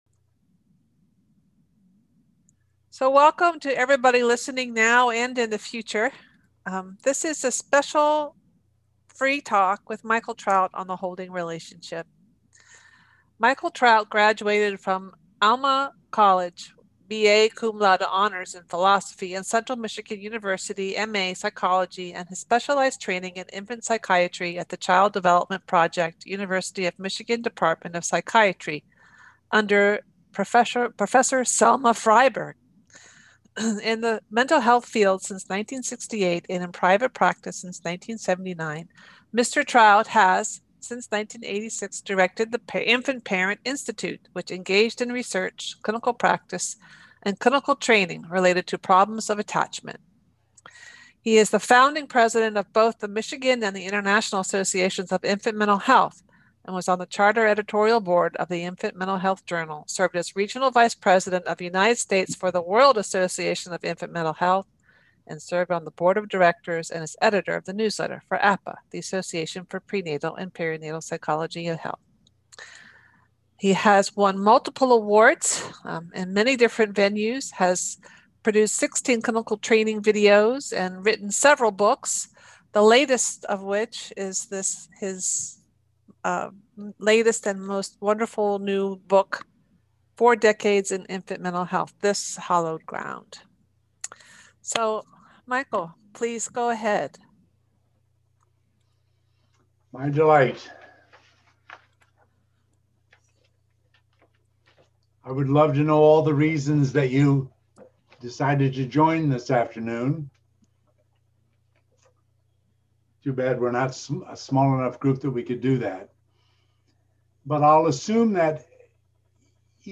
Presentations with Practical Skills for PPN Healing